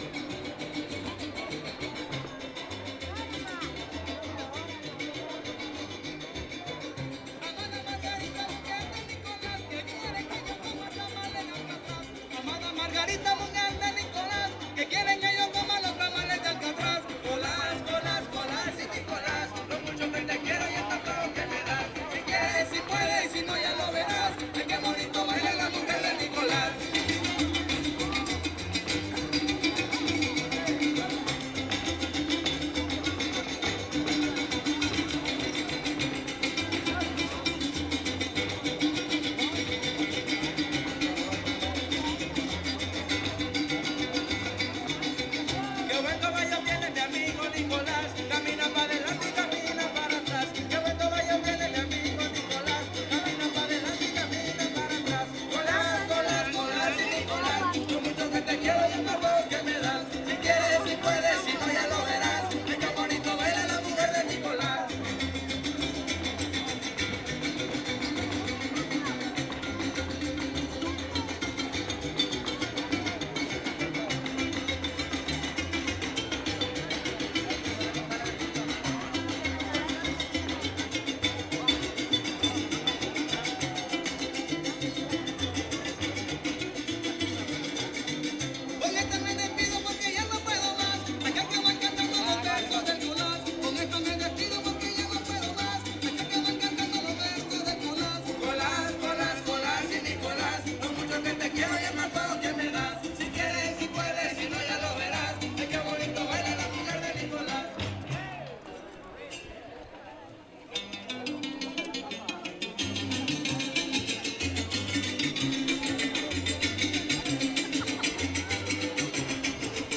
• Siquisirí (Grupo musical)
Concurso Estatal de Fandango